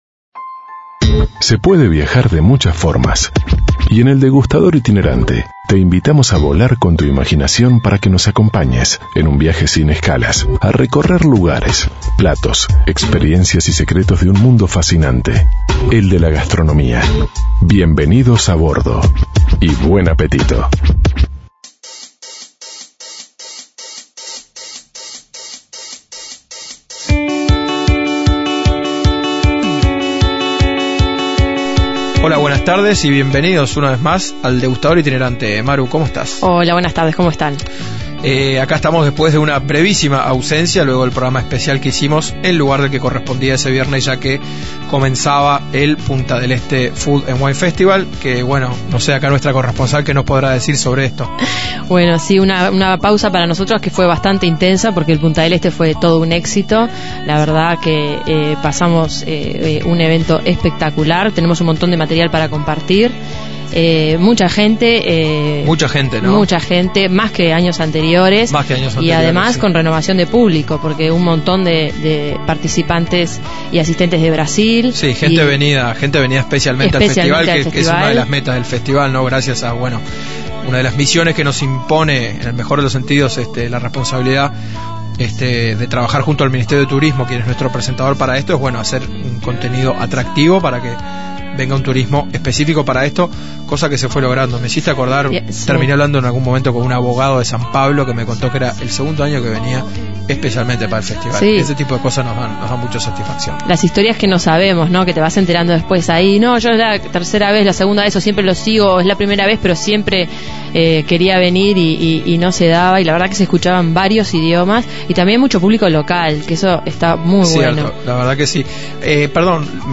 Los fuegos se apagaron, las cacerolas están limpias y los chefs están de vuelta en sus cocinas...pero nosotros seguimos relamiéndonos con los manjares que se sirvieron en el Punta Food & Wine 2013. Por eso, para cerrar el ciclo, repasamos las voces de los maestros que nos acompañaron en el gran festival gastronómico.